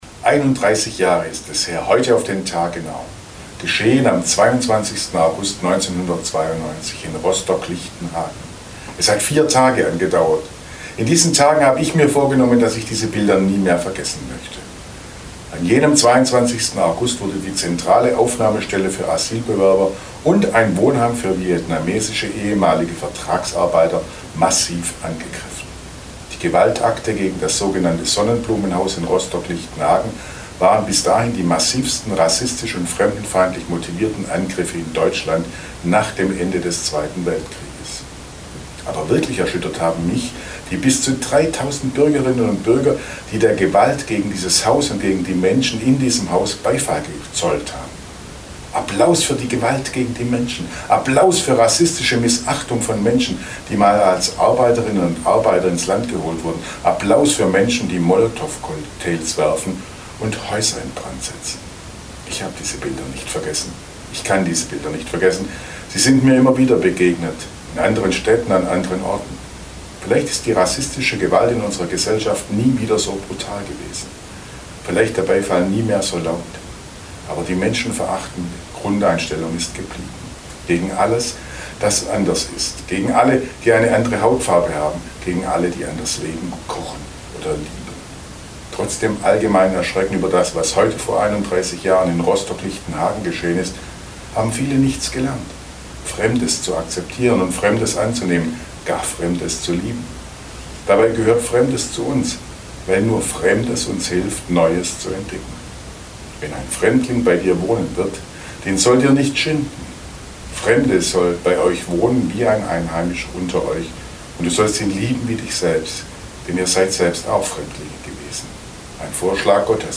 Radioandacht vom 22. August